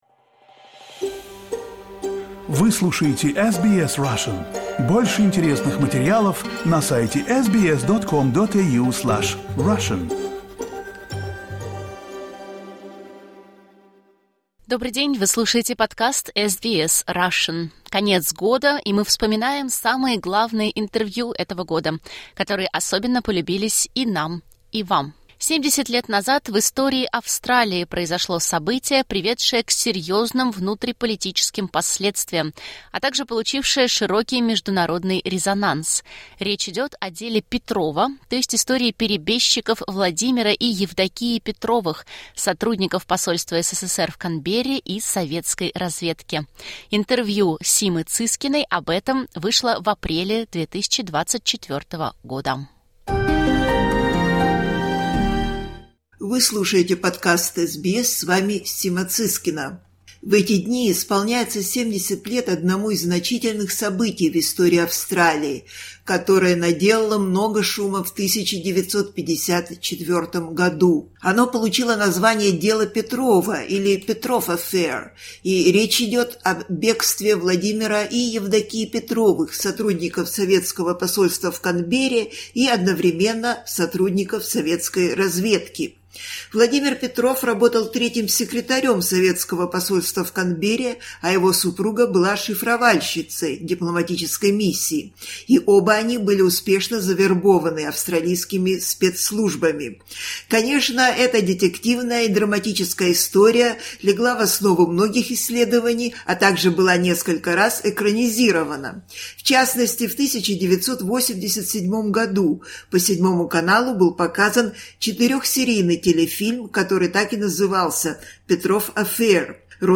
Лучшие интервью 2024. Шпионская история Petrov affair